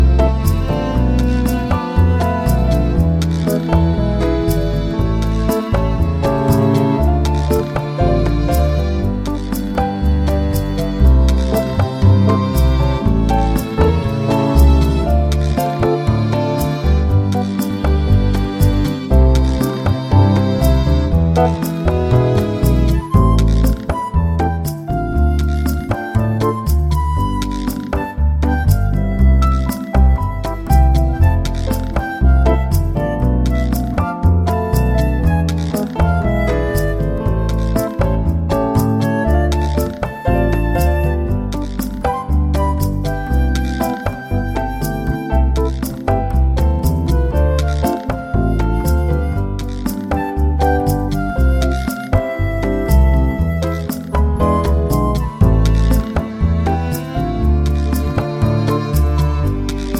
Down 5 Semitones for Female